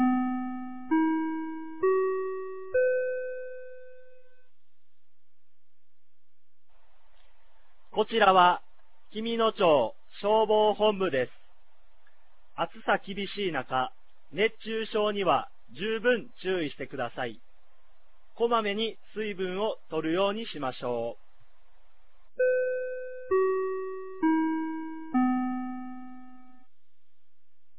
2025年07月05日 16時00分に、紀美野町より全地区へ放送がありました。